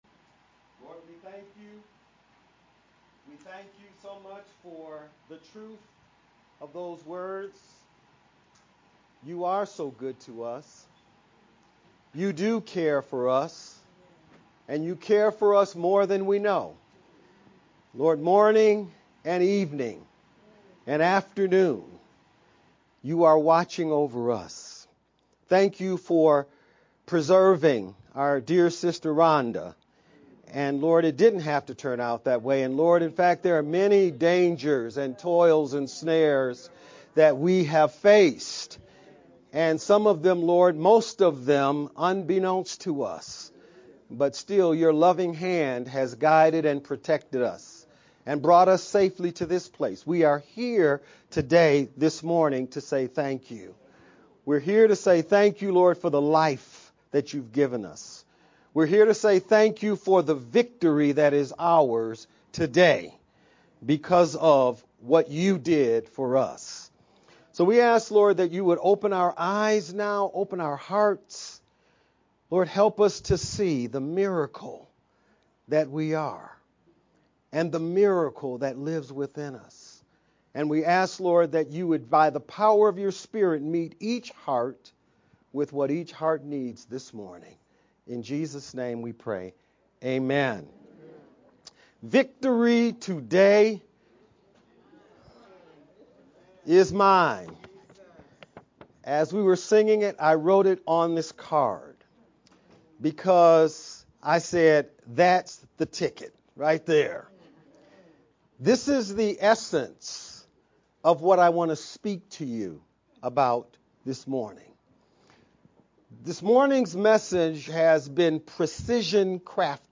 VBCC-video-edited-sermon-only-7-20-Made-with-Clipchamp_Converted-CD.mp3